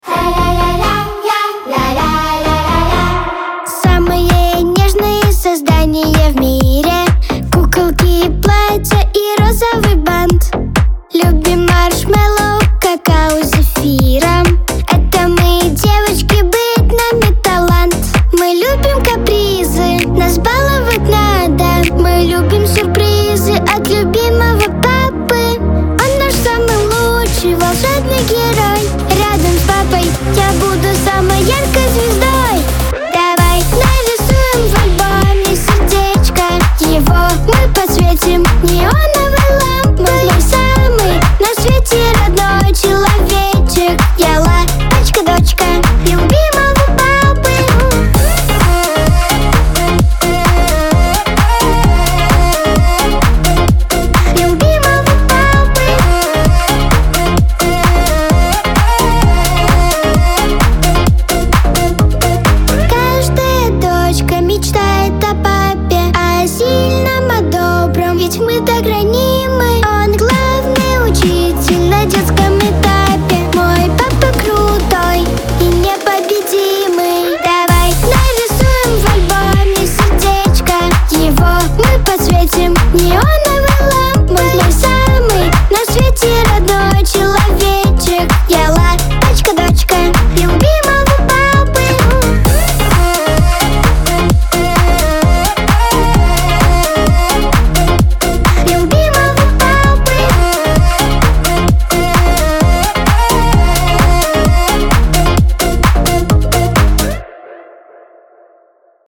эстрада , диско
pop